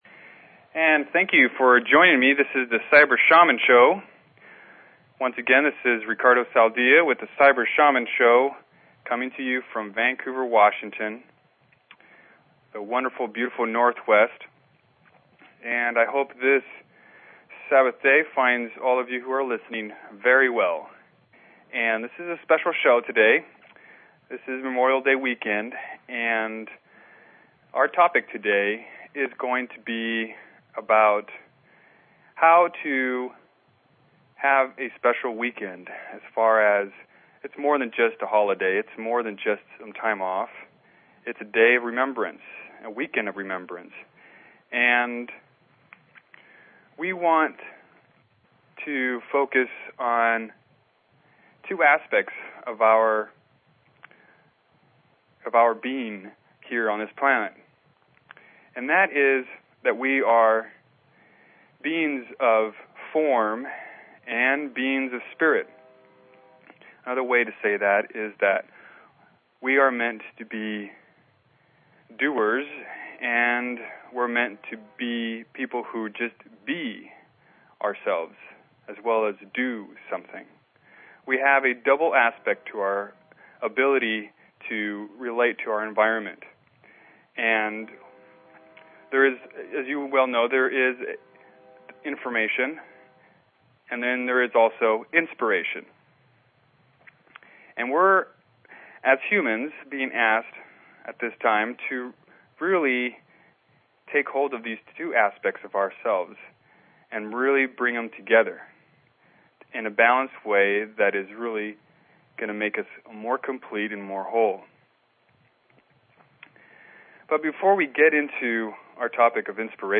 Talk Show Episode, Audio Podcast, Cyber_Shaman and Courtesy of BBS Radio on , show guests , about , categorized as